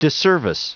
Prononciation du mot disservice en anglais (fichier audio)
Prononciation du mot : disservice